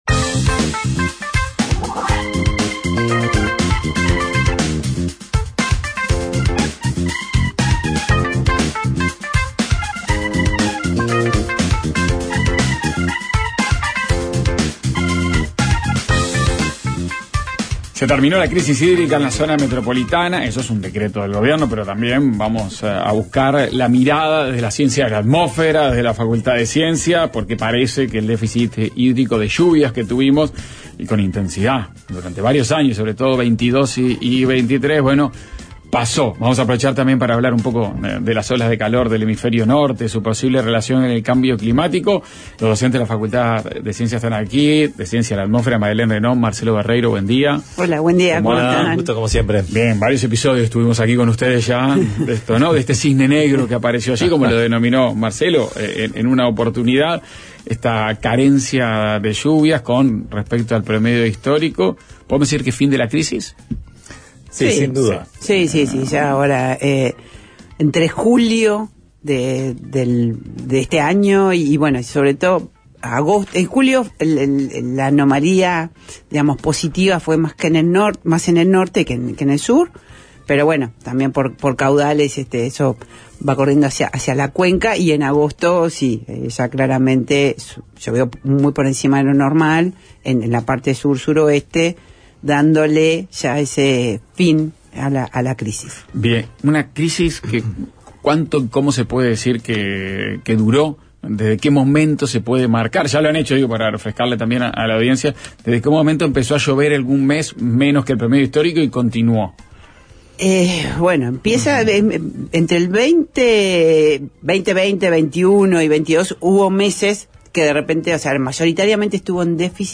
Ronda